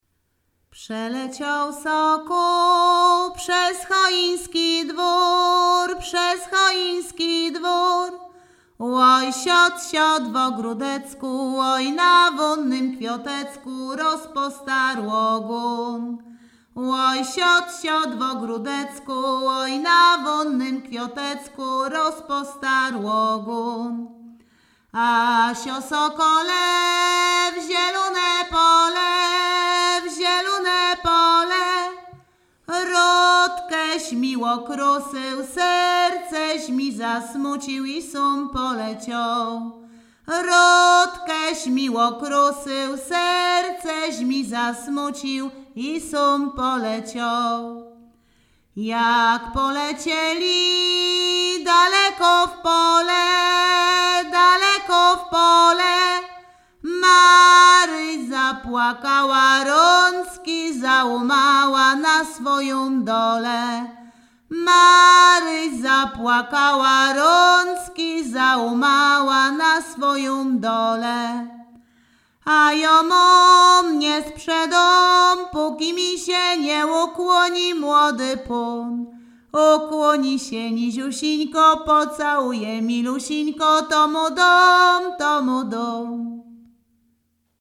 Sieradzkie
województwo łódzkie, powiat sieradzki, gmina Sieradz, wieś Chojne
Weselna
Śpiewaczki z Chojnego
liryczne miłosne weselne wesele